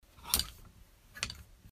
15. Сняли плечико с одеждой